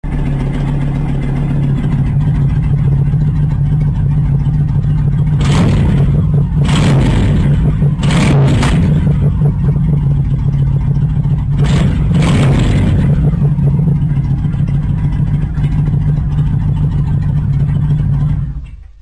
Auspuff-Sounds